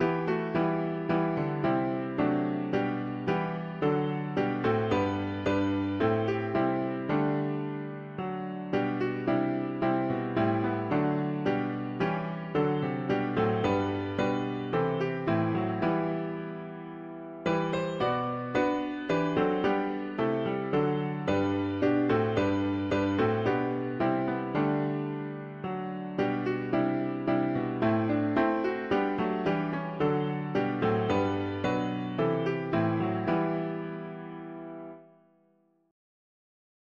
Key: E minor Meter